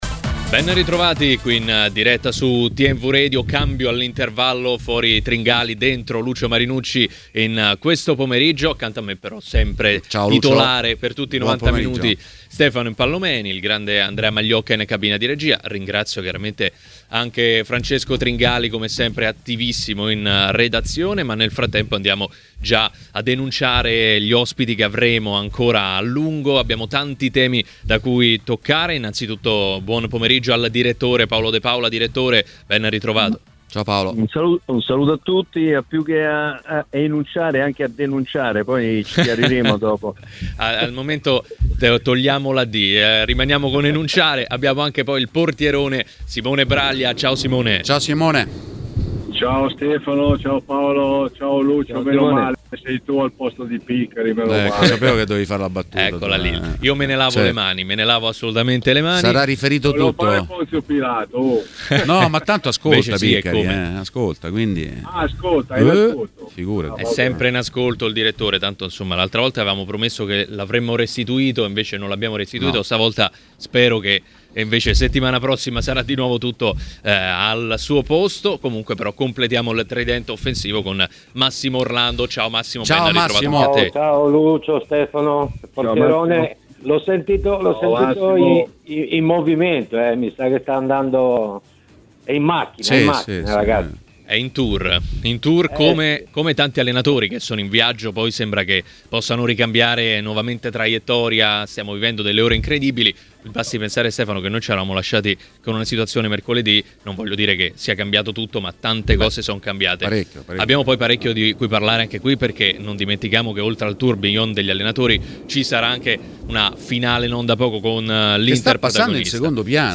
Durante Maracanà , nel pomeriggio di TMW Radio